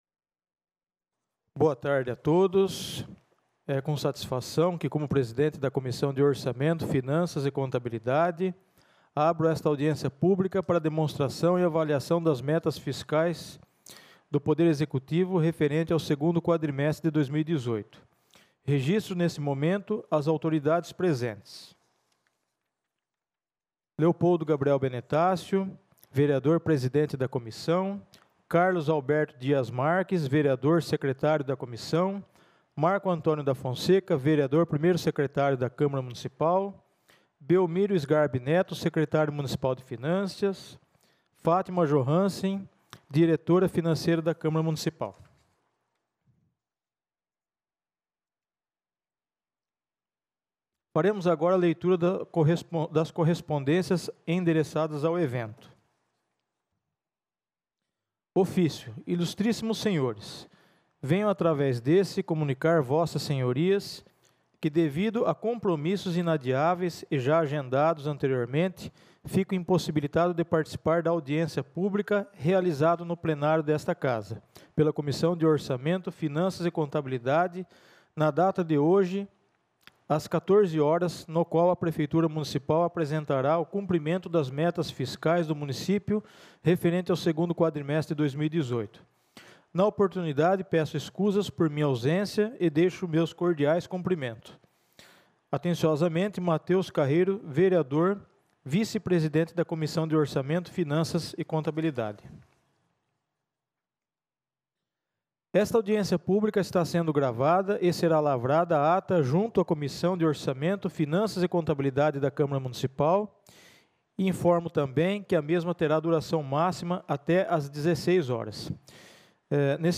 Audiência pública para apresentação do cumprimento das metas fiscais 2º quadrimestre pelo Executivo Municipal.